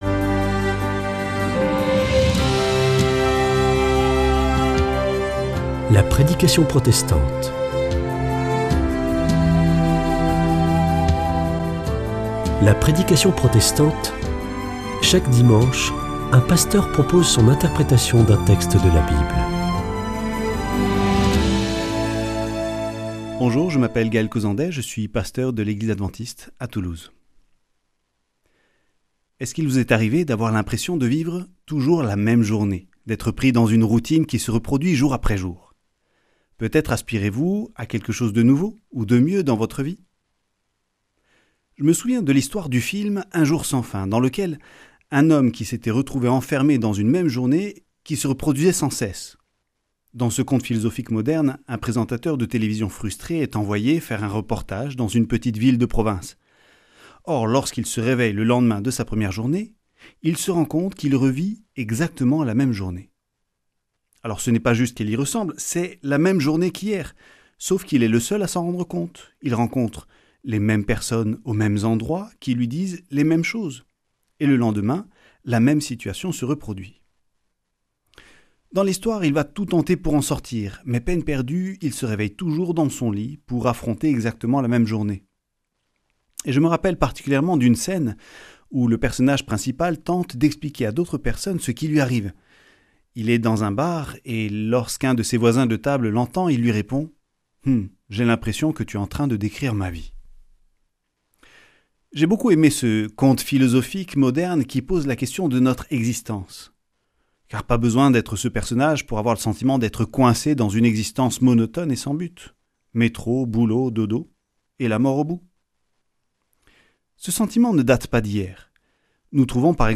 La prédication protestante